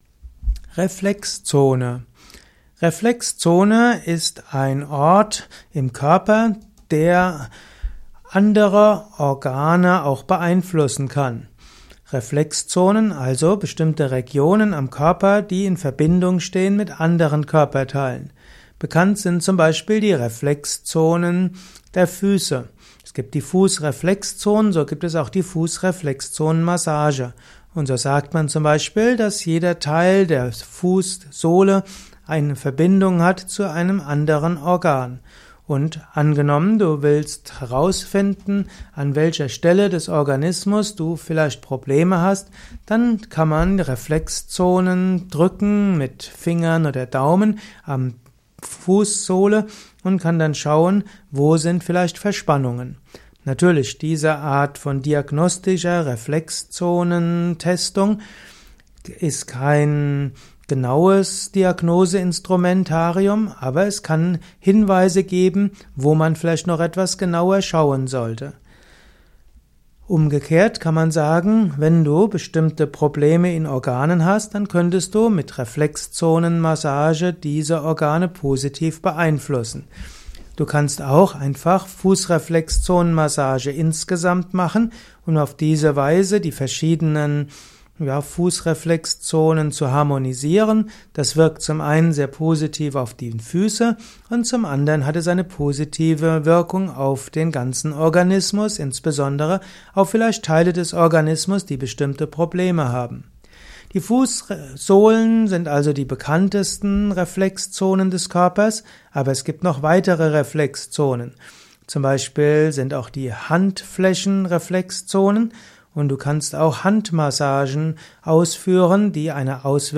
Ein kurzes Vortragsvideo über die Reflexzone